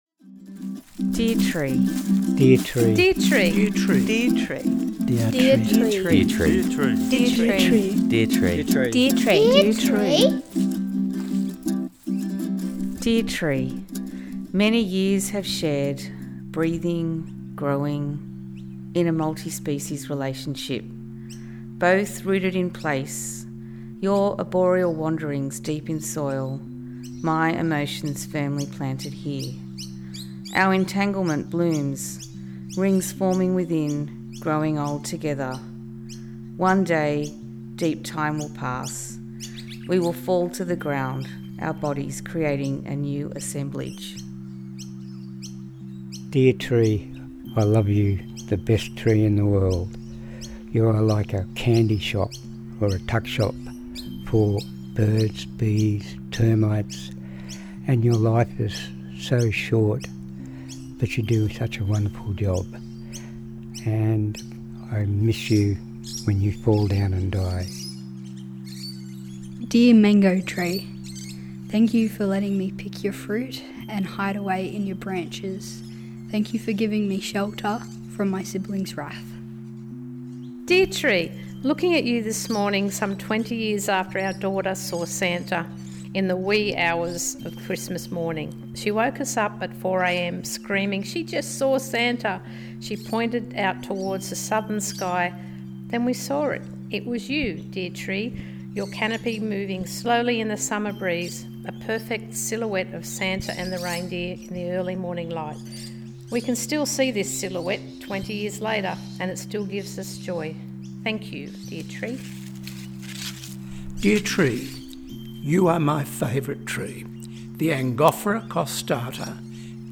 “Dear Tree" is a listening experience - a collection of recordings of local people talking from the heart to their favourite tree, acknowledging and contemplating the position of nature within their lives.
The audio experience includes diverse voices from our community, who have contributed their personal thoughts and reflections about trees and nature and provide a thoughtful, positive experience for visitors.